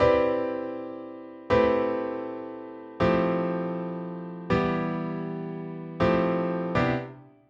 We substituted that red Cmaj9 chord for an Emin7 notated in blue. This unique sound helps drive the motion forward because instead of playing the I chord which creates a sound of resolution, the iii or minor 3 chord keeps the motion of the progression moving.